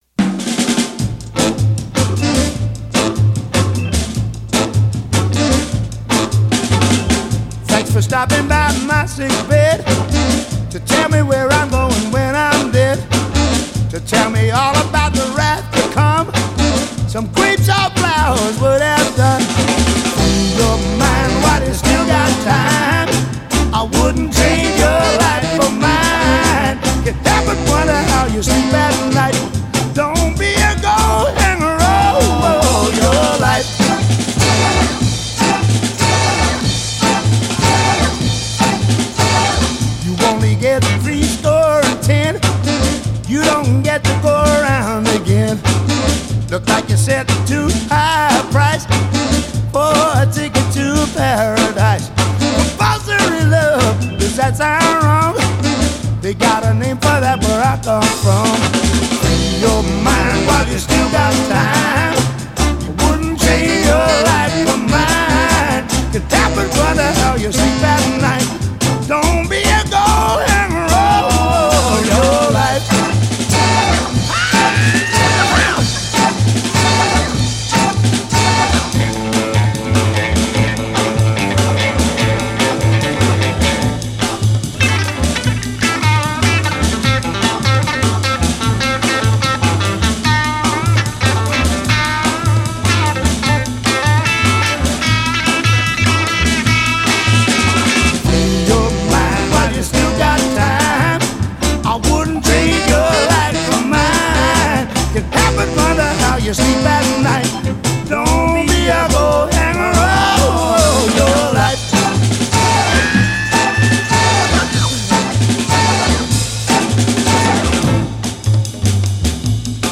soul-slot